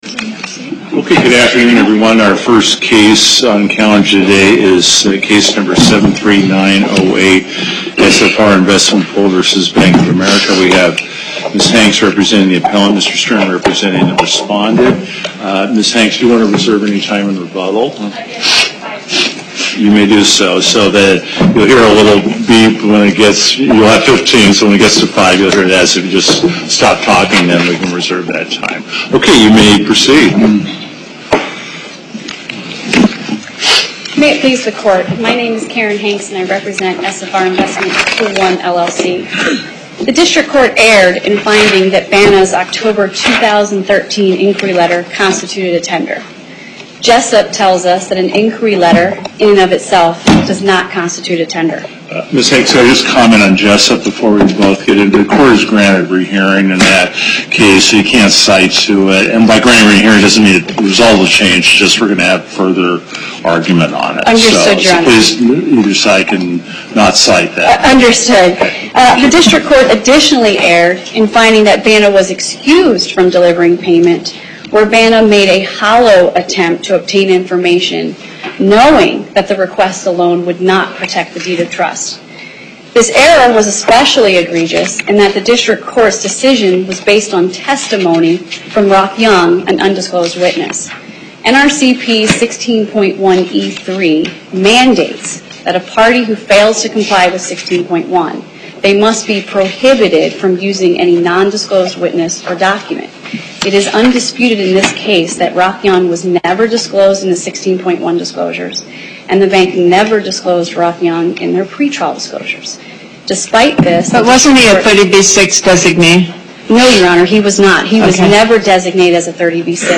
Location: Las Vegas Before the En Banc Court, Chief Justice Gibbons Presiding